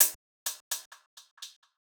Hihat Roll 4.wav